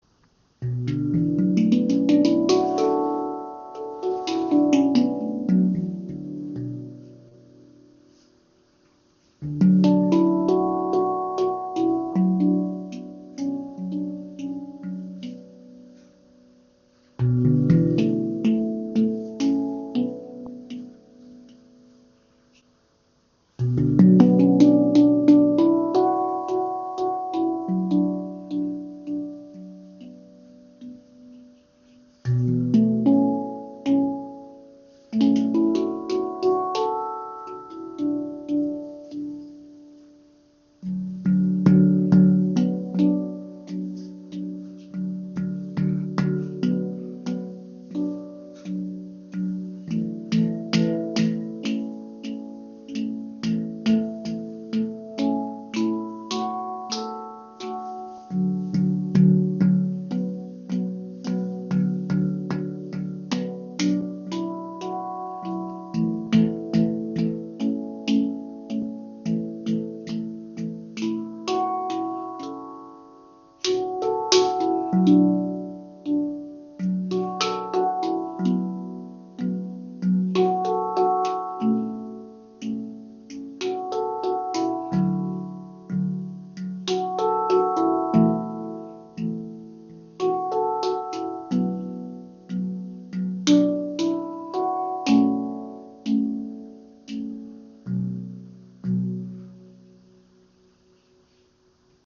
Klangbeispiel
Diese MAG Handpan aus Ungarn zeichnet sich durch ihre besonders schöne Oberfläche und Klangfarbe aus.
Sie hat leichte, hohe Töne die Dir die Möglichkeiten bieten melodiöse und perkussive Elemente zu spielen.
Des Weiteren faszinieren uns die besondere Wärme und Präsenz des Instrumentes. Alle Klangfelder sind sehr gut gestimmt und lassen sich auch mit fortgeschrittenen Spieltechniken anspielen.